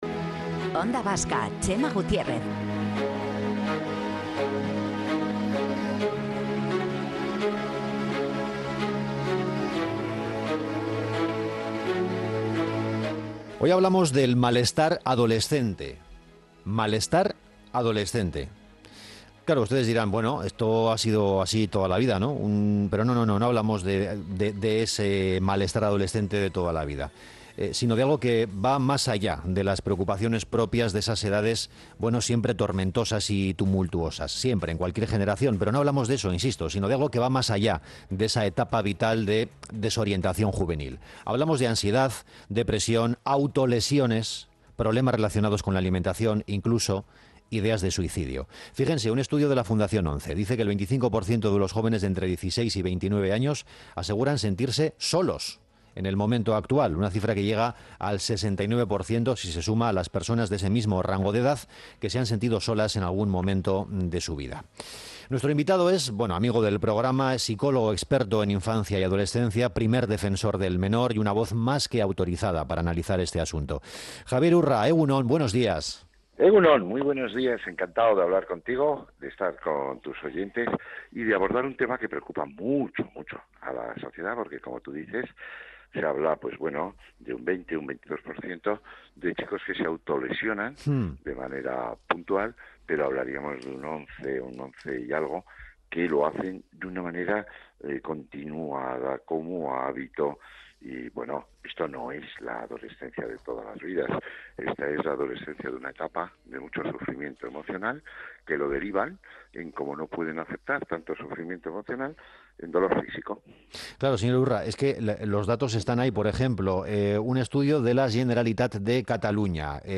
En ONDA VASCA lo hemos abordado de la mano del experto en infancia y adolescencia y primer defensor del menor Javier Urra.